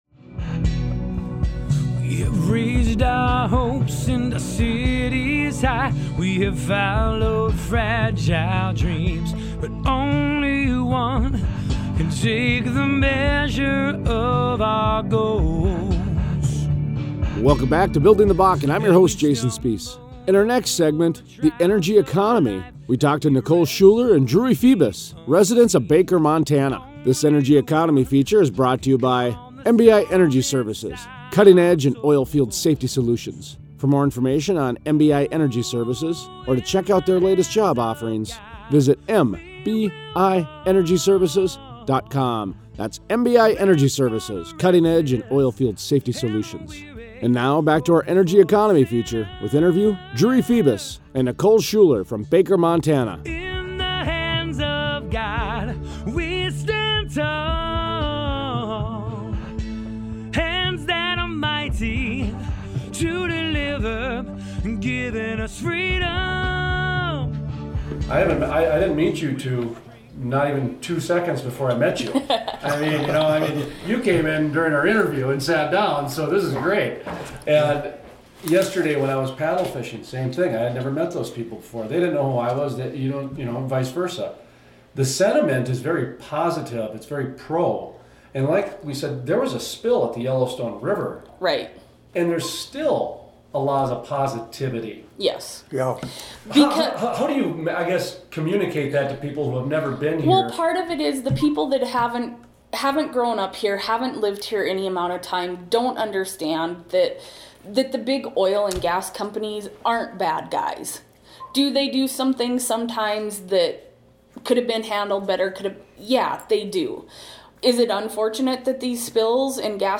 Energy Economy Interviews